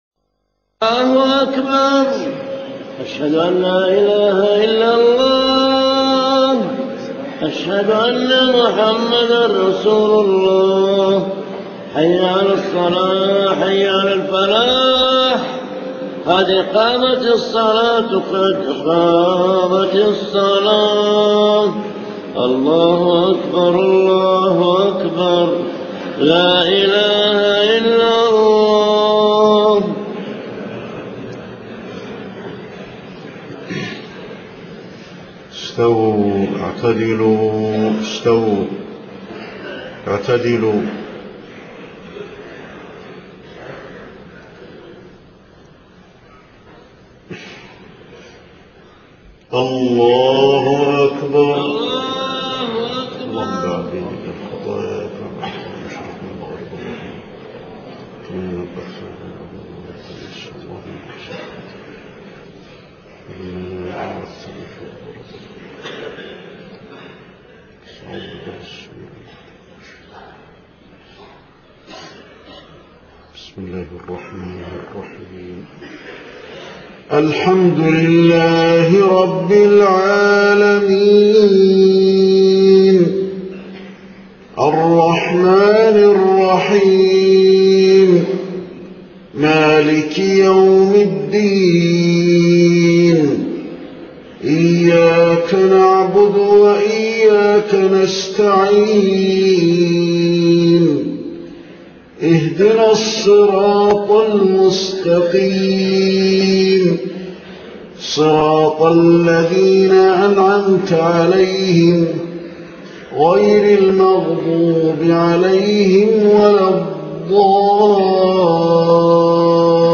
صلاة الفجر 6 محرم 1430هـ سورة النازعات كاملة > 1430 🕌 > الفروض - تلاوات الحرمين